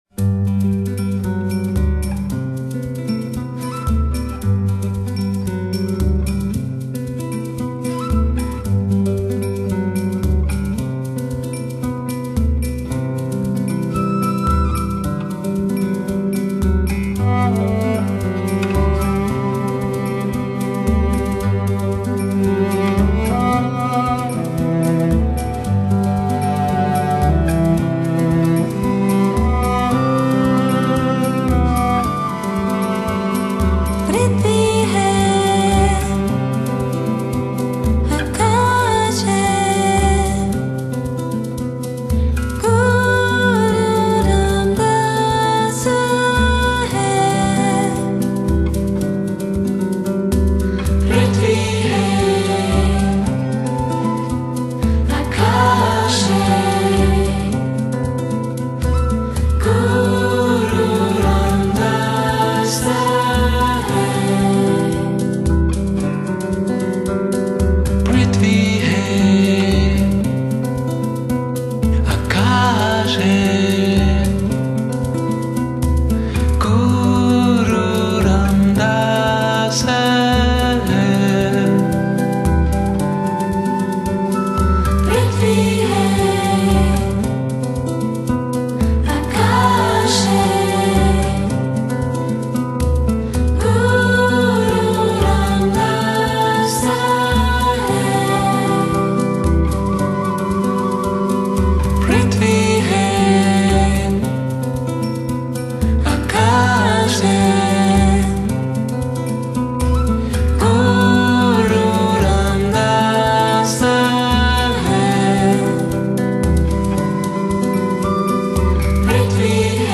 歐美New Age